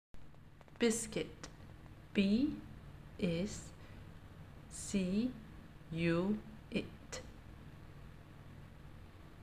1. Biscuit – “b” “is” “c” “u” “it” (